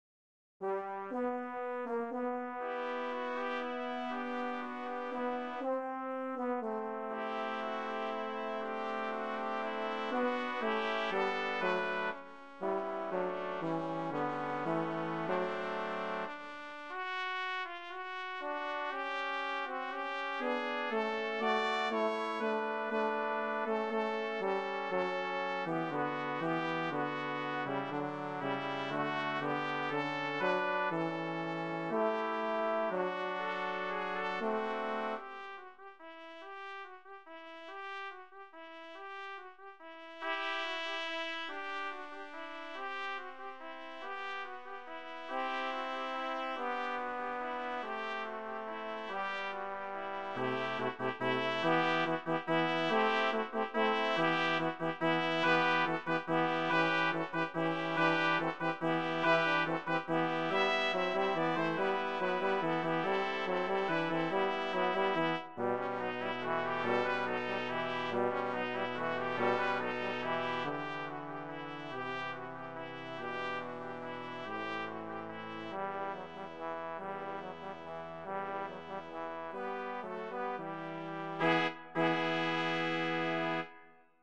Voicing: Brass Quartet